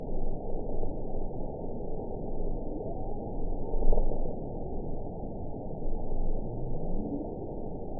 event 919827 date 01/25/24 time 11:55:00 GMT (1 year, 3 months ago) score 8.43 location TSS-AB07 detected by nrw target species NRW annotations +NRW Spectrogram: Frequency (kHz) vs. Time (s) audio not available .wav